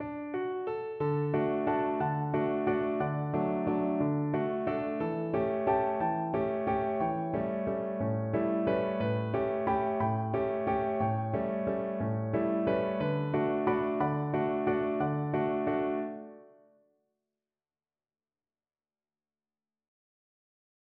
요한 슈트라우스 2세, 아름다운 도나우강 왈츠